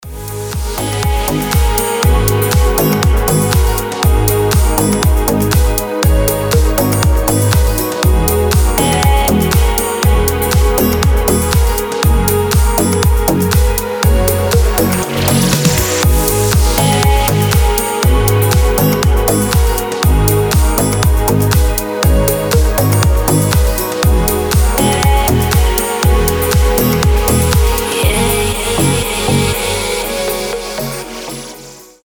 • Качество: 320, Stereo
громкие
deep house
без слов
nu disco
звонкие
Indie Dance
Мелодичный рингтон со звонкой гитарой